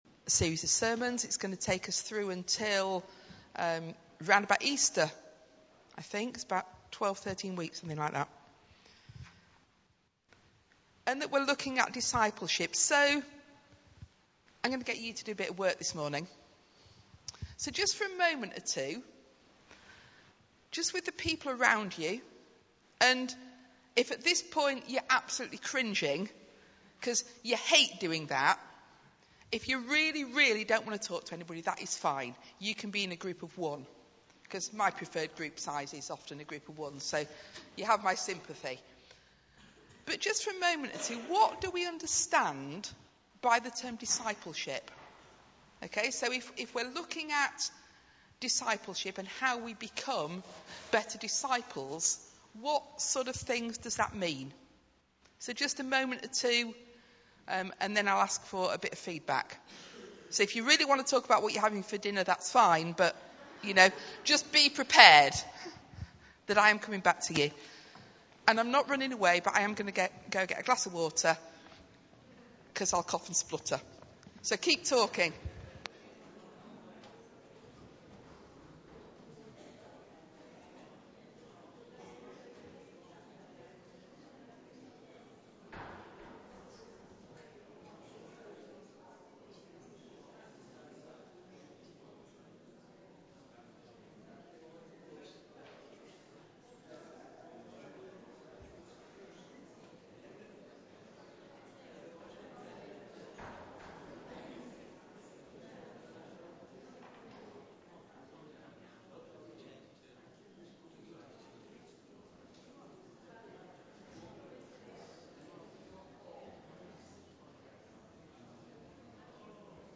The first of our current sermon series on Discipleship.